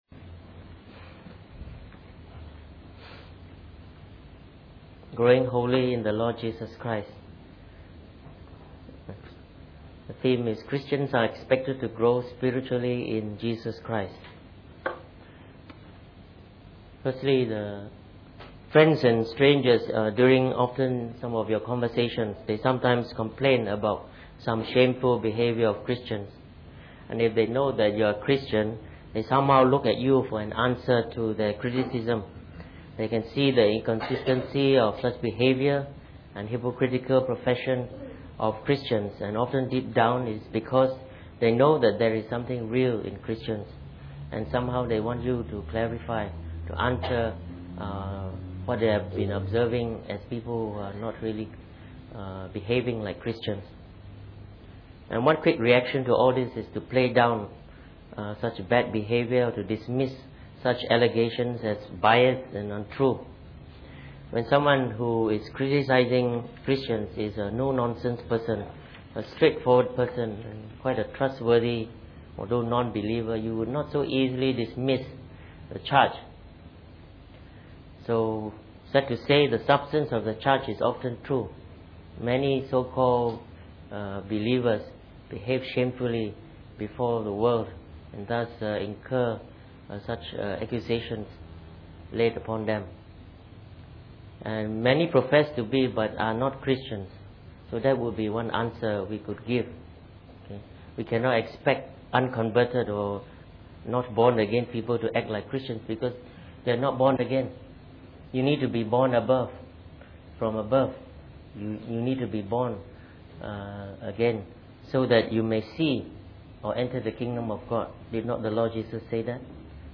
Preached on the 24th of July 2011.